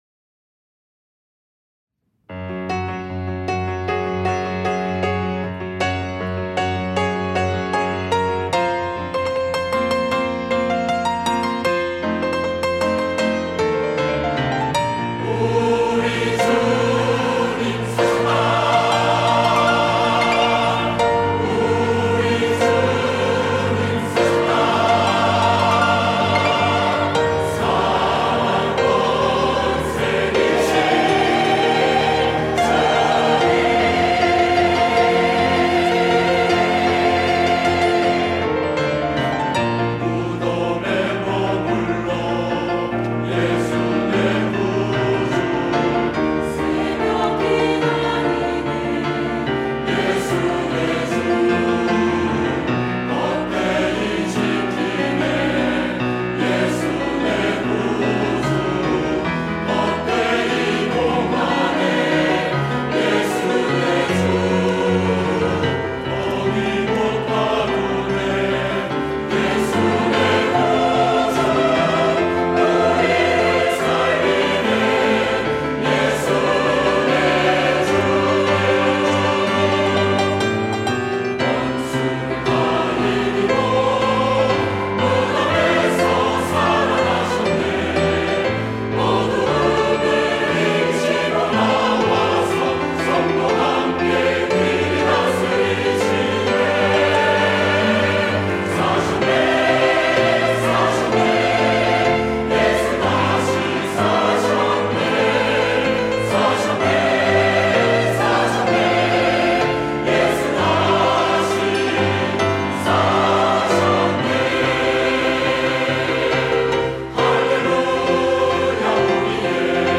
할렐루야(주일2부) - 우리 주님 사셨다
찬양대